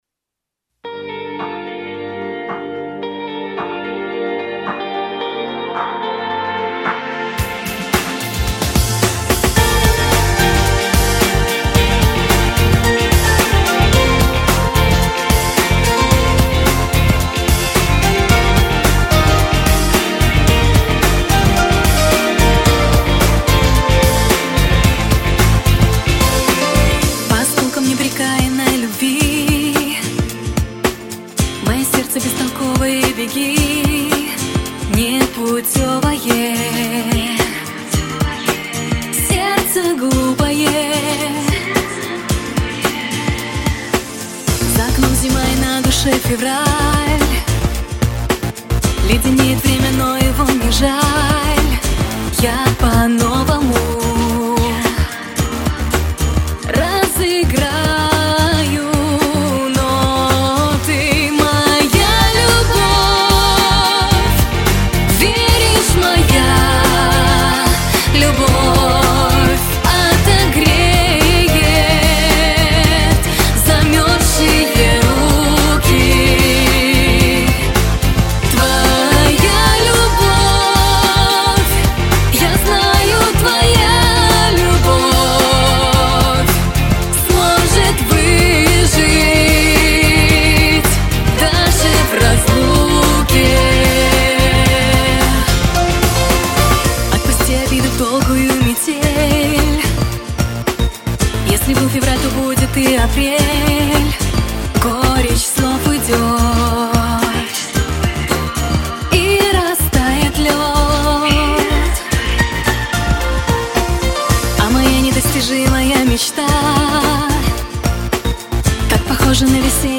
Важно мнение касательно "посадки" вокала в миксе.
навалено сильно с заполнением , спектрально насыщенно